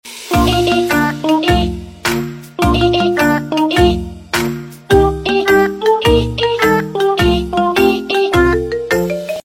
oi oi cat mingle ringtone sound effects free download